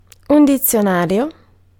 Ääntäminen
Synonyymit vocabolario lessico Ääntäminen : IPA: [un ditsjoˈnarjo] Tuntematon aksentti: IPA: /ditsjoˈnarjo/ Haettu sana löytyi näillä lähdekielillä: italia Käännös Ääninäyte Substantiivit 1. dictionary US UK Suku: m .